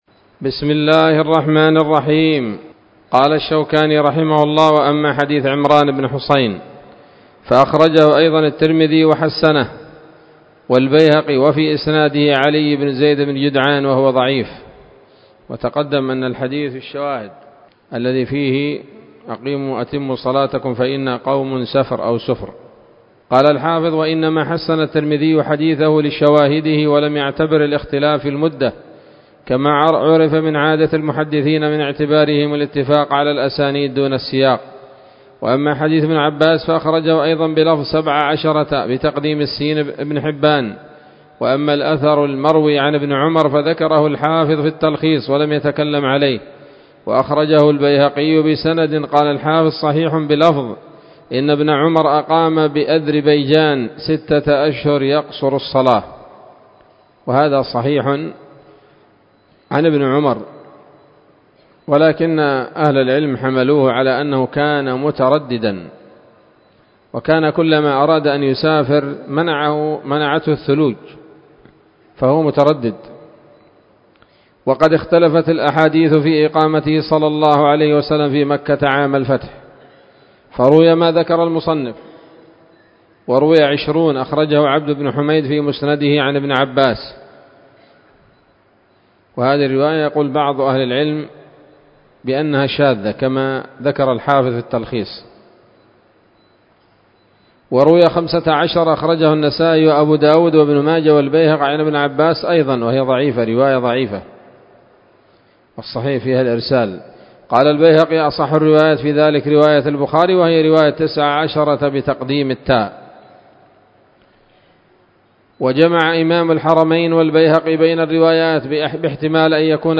الدرس السادس وهو الأخير من ‌‌‌‌أَبْوَاب صَلَاةِ الْمُسَافِرِ من نيل الأوطار